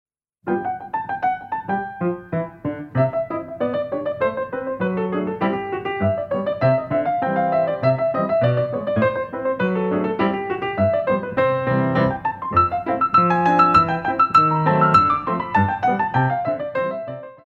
Batterie ou sauts face à la barre + rapide